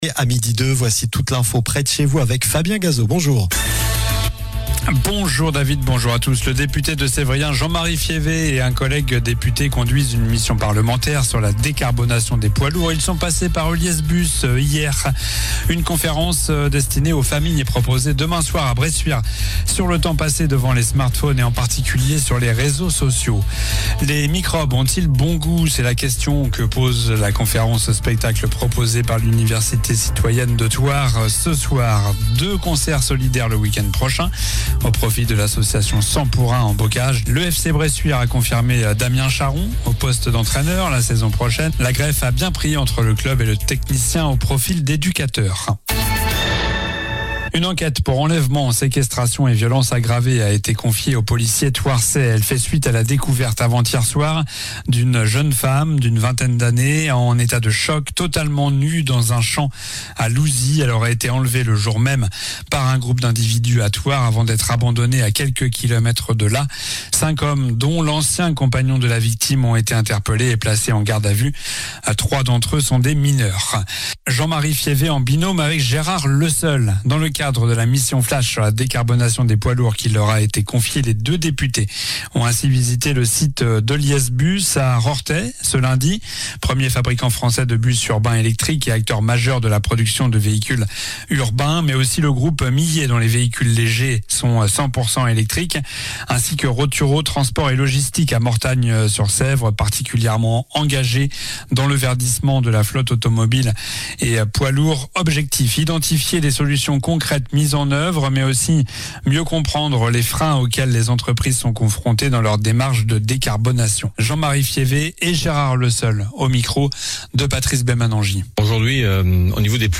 Journal du mardi 24 mars (midi)